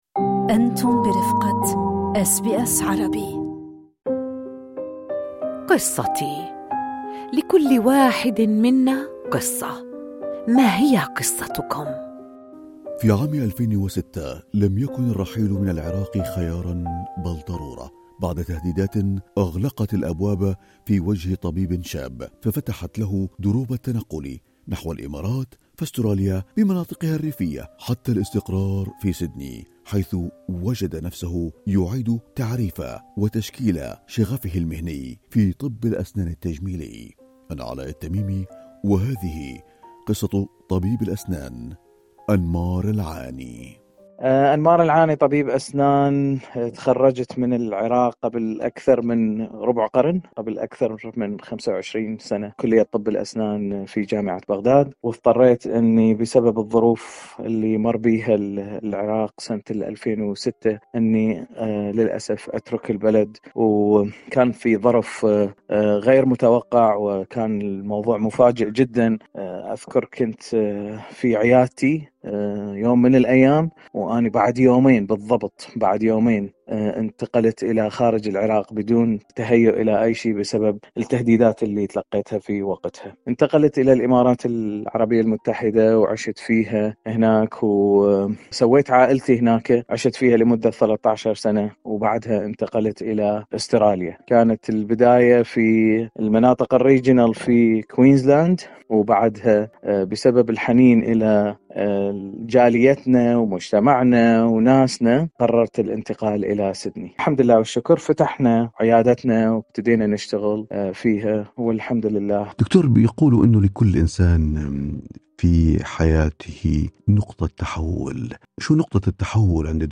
للاستماع لتفاصيل اللقاء، اضغطوا على زر الصوت في الأعلى.